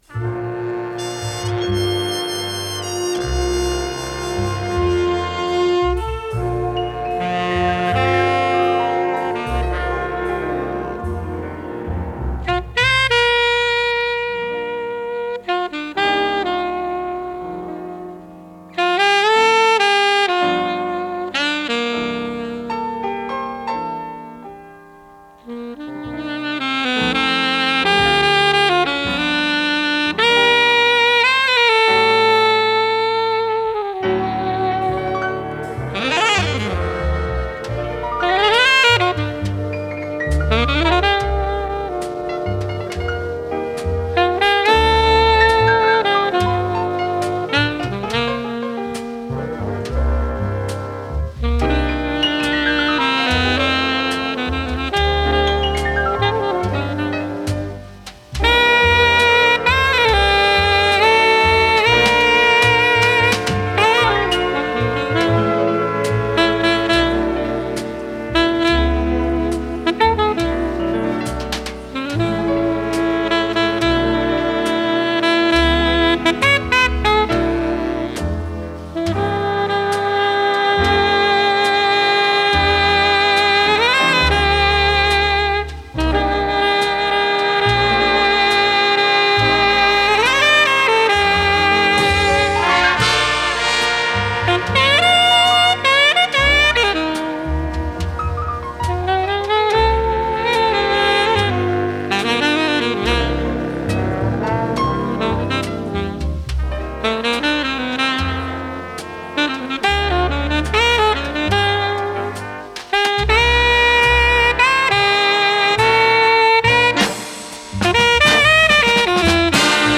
с профессиональной магнитной ленты
альт-саксофон
ВариантДубль моно